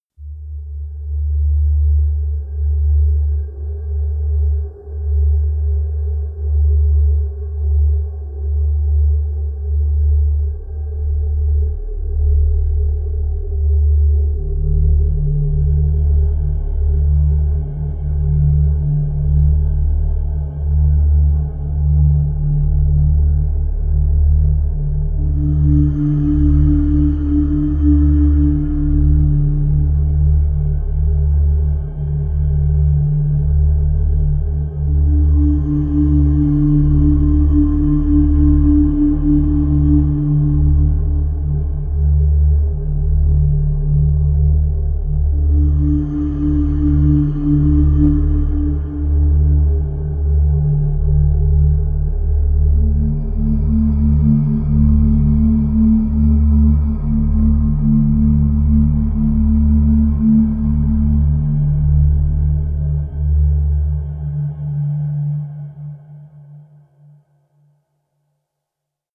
Royalty free Pads for your projects.